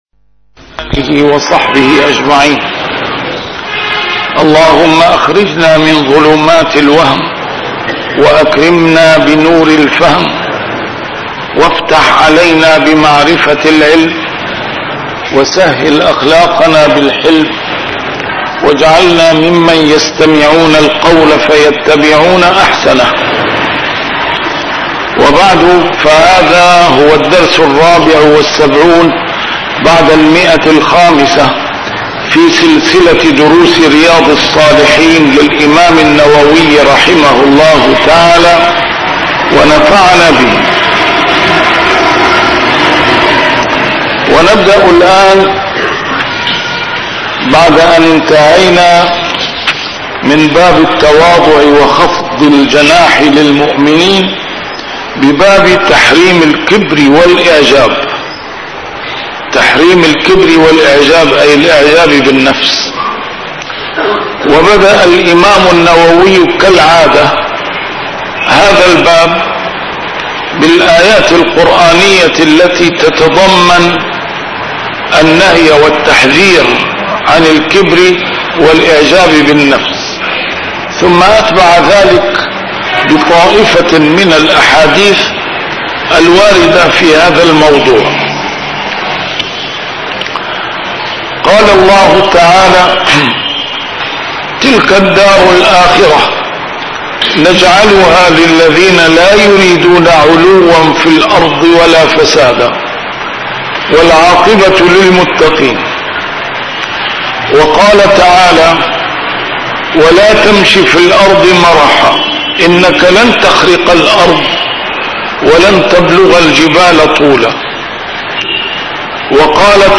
A MARTYR SCHOLAR: IMAM MUHAMMAD SAEED RAMADAN AL-BOUTI - الدروس العلمية - شرح كتاب رياض الصالحين - 574- شرح رياض الصالحين: تحريم الكبر والإعجاب